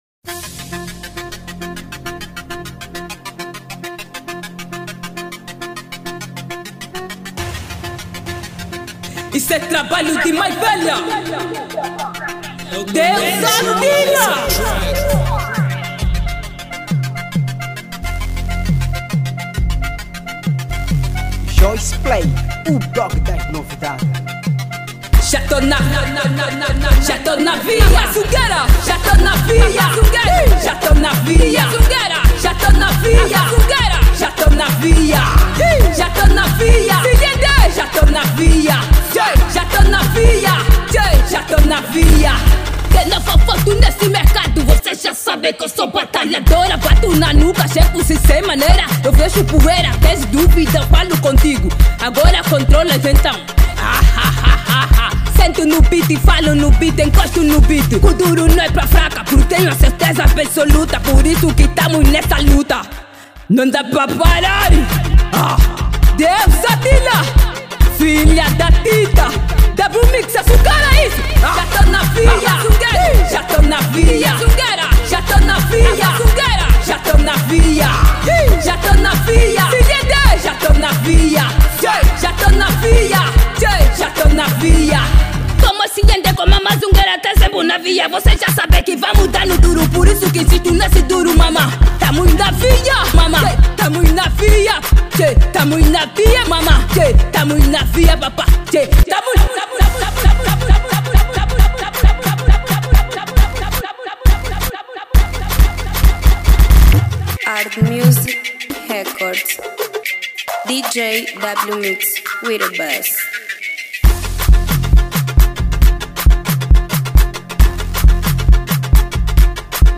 Categoria: Kuduro